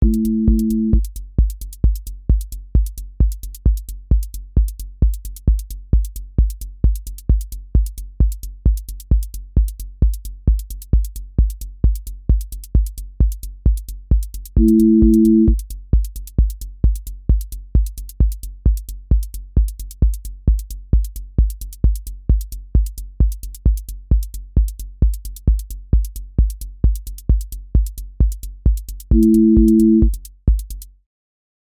QA Listening Test techno Template
techno pressure with driven motion
• voice_kick_808
• voice_hat_rimshot
• voice_sub_pulse
• tone_brittle_edge
• motion_drift_slow